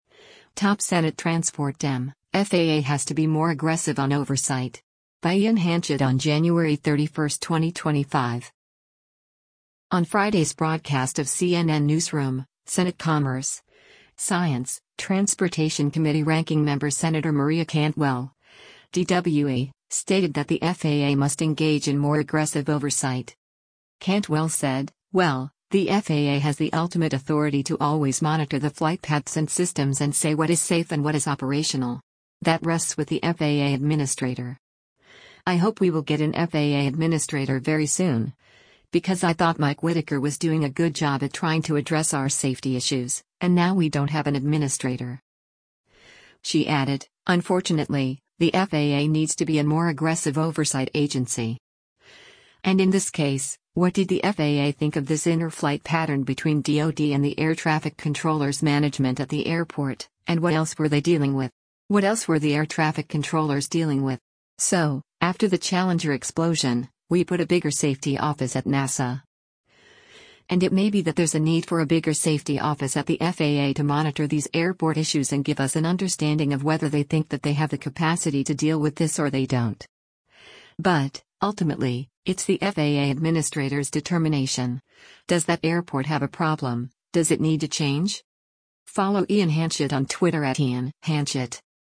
On Friday’s broadcast of “CNN Newsroom,” Senate Commerce, Science, Transportation Committee Ranking Member Sen. Maria Cantwell (D-WA) stated that the FAA must engage in more aggressive oversight.